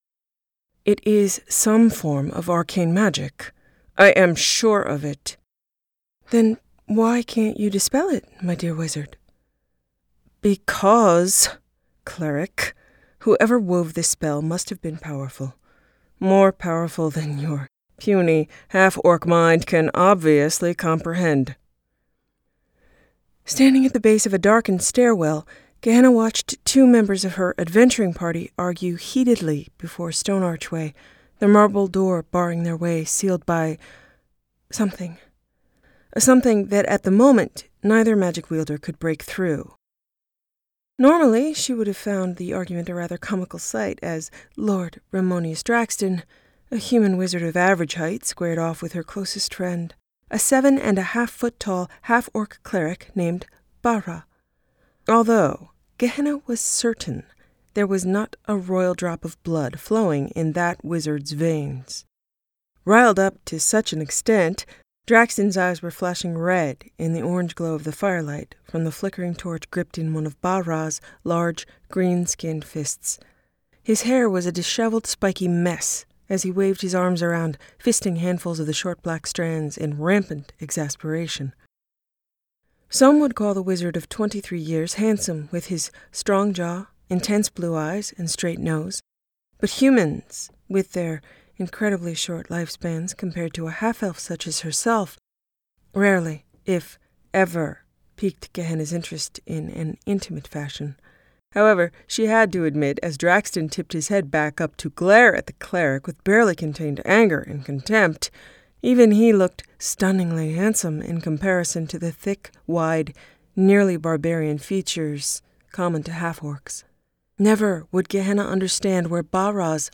The audiobook version of Winds of Fate in cooperation with B&R Publishing, available on Amazon, ITunes and Audible is Here.